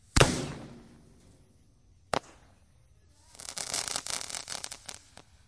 烟花 " Fireworks08
描述：使用Tascam DR05板载麦克风和Tascam DR60的组合使用立体声领夹式麦克风和Sennheiser MD421录制烟花。我用Izotope RX 5删除了一些声音，然后用EQ添加了一些低音和高清晰度。
Tag: 高手 焰火 裂纹